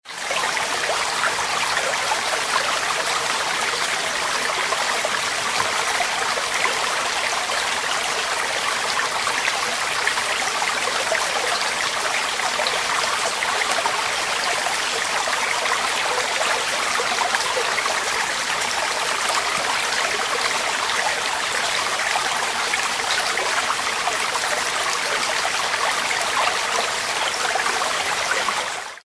Il ruscello di montagna
Ruscello di montagna.mp3